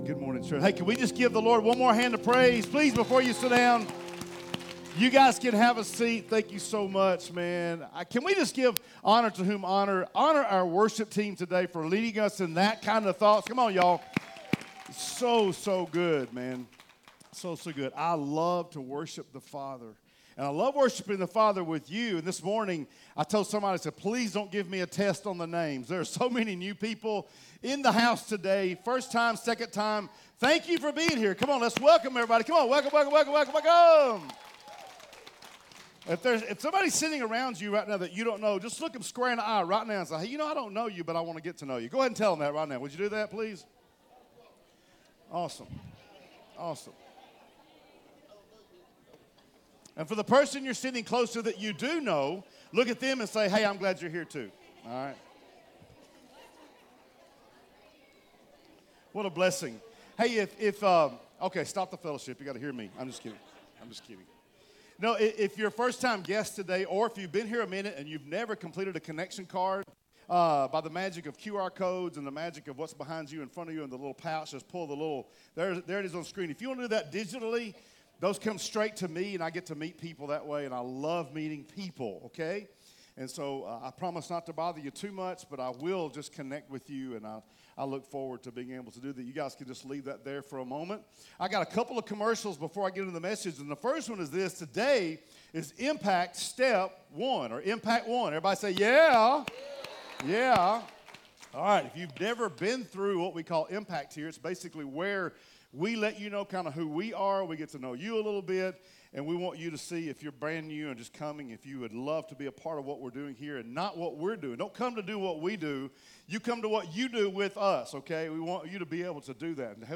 Sermons | Discovery Church